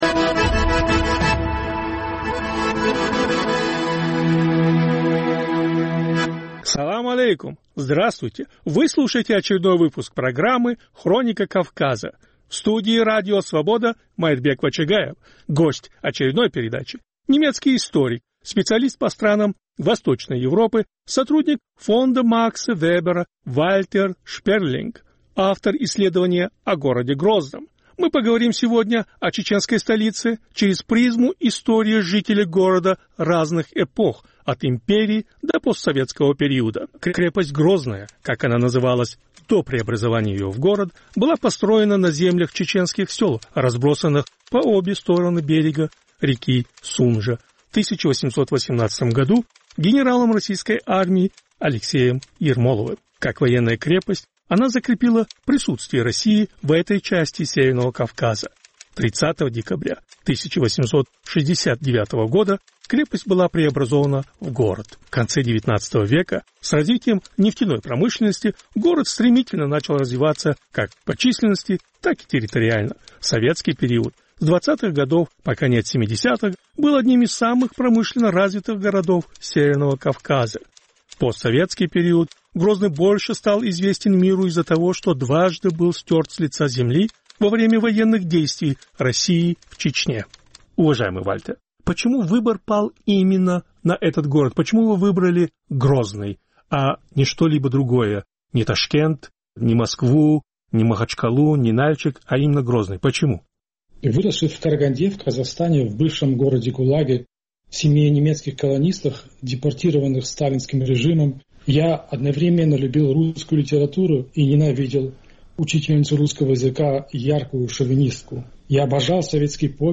Повтор эфира от 26 ноября 2023 года.